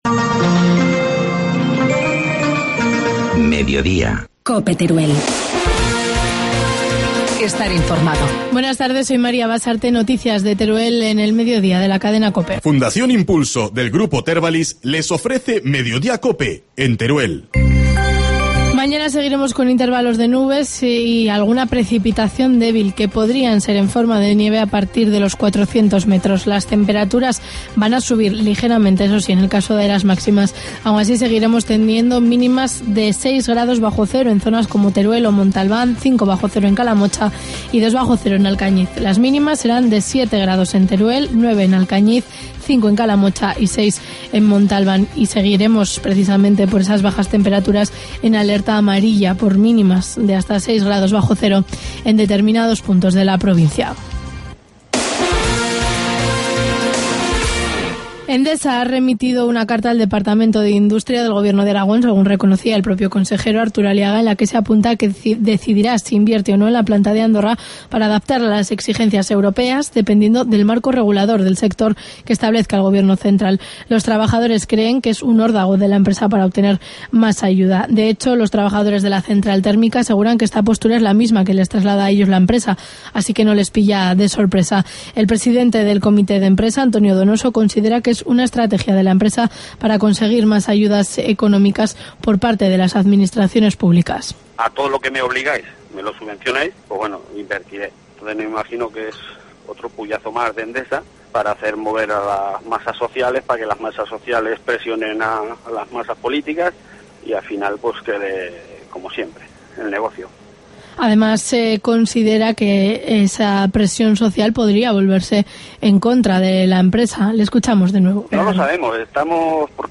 Informativo mediodía, martes 26 de febrero